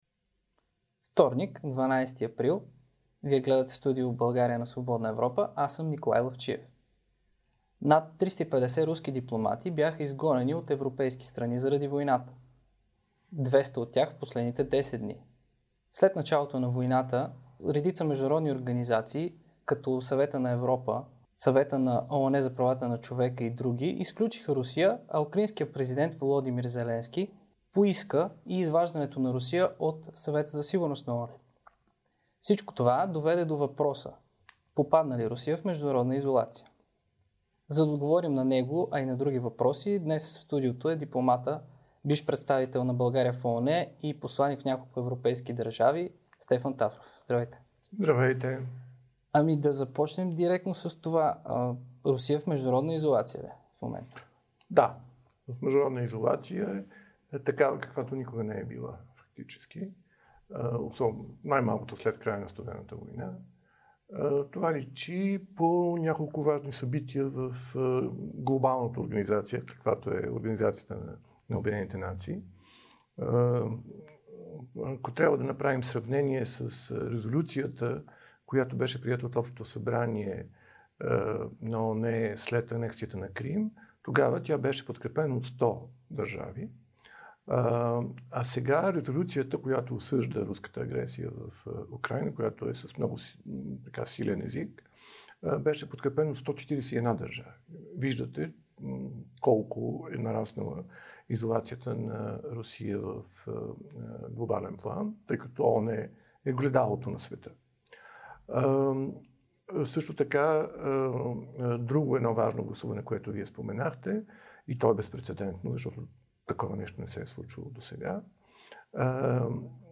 Това каза дипломатът Стефан Тафров в Студио България на Свободна Европа. Посланик Митрофанова се отличава в негативен смисъл спрямо своите колеги руски посланици в другите европейски страни, каза още той.